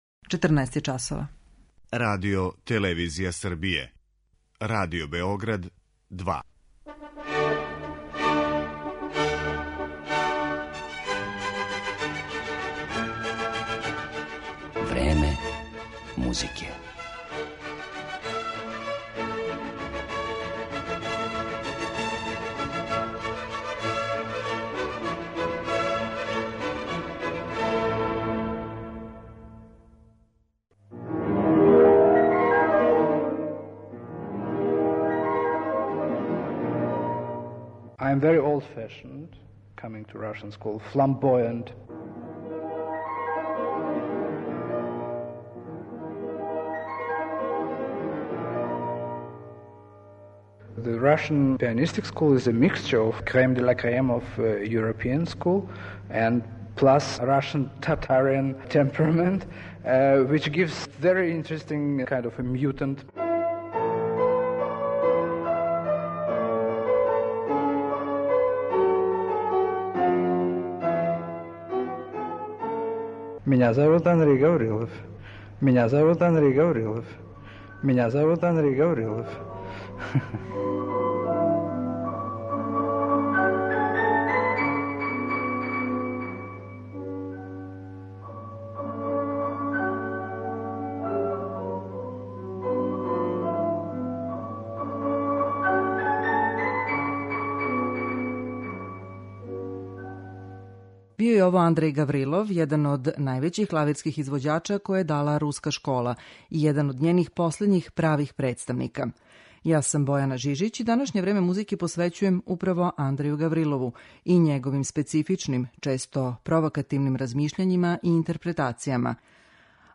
Данашња емисија Време музике посвећена је овом провокативном руском пијанисти са којим је снимљен и интервју приликом његовог гостовања у Београду 2013. године.